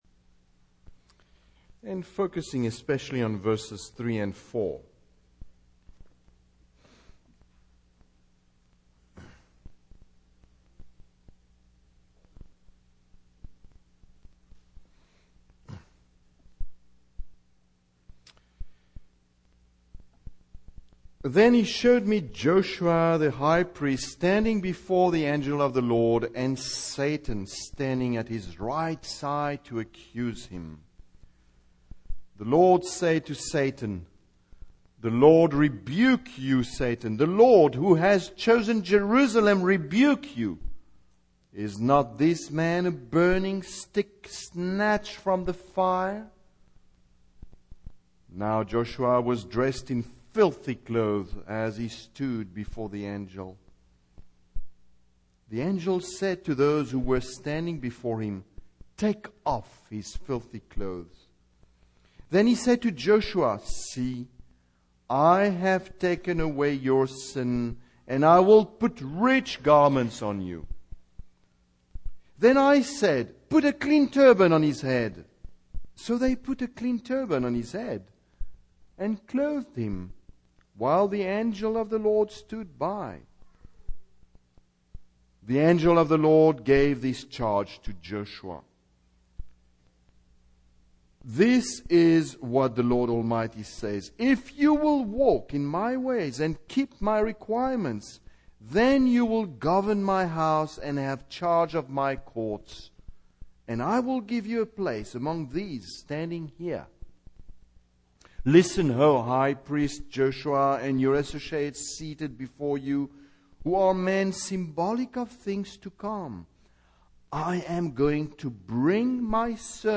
Series: Single Sermons
Service Type: Evening